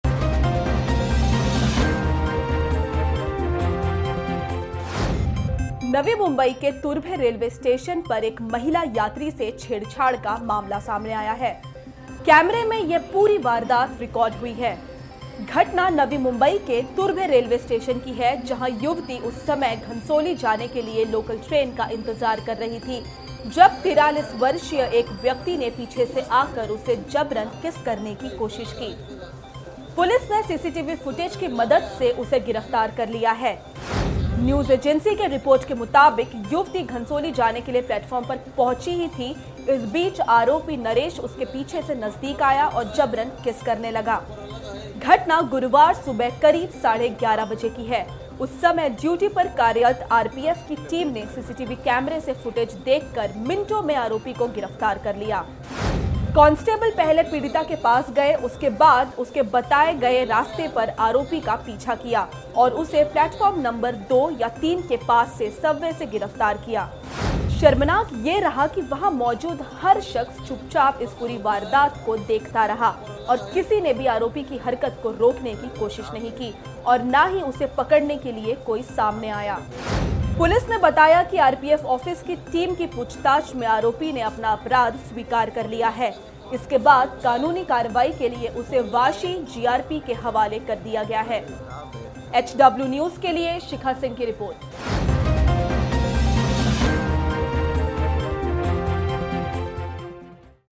News Report / नवी मुंबई में लोकल का इंतज़ार कर रही महिला से ज़बरन किस करने का मामला आया सामने